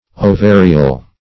Ovarial \O*va"ri*al\